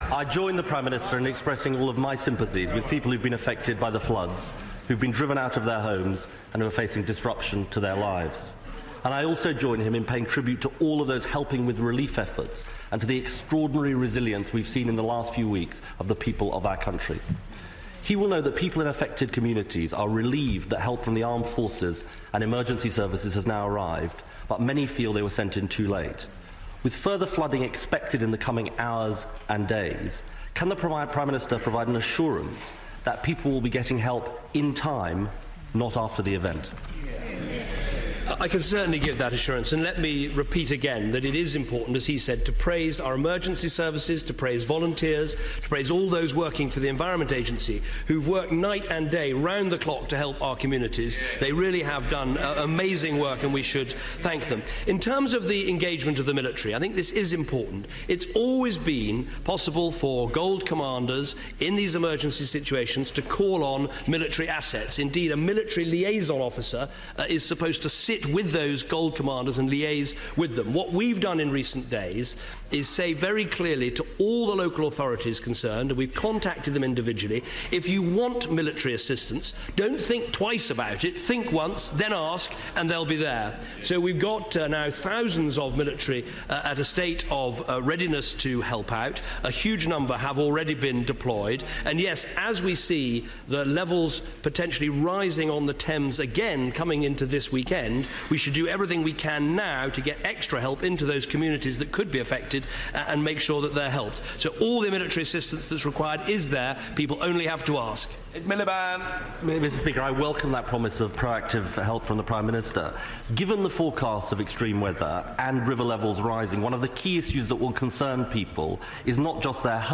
House of Commons, 12 February 2014